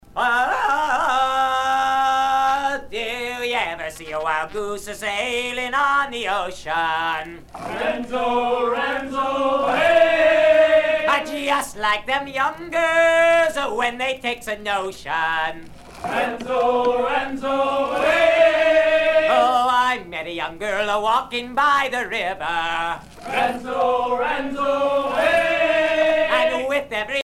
Sea chanteys and sailor songs
Pièce musicale éditée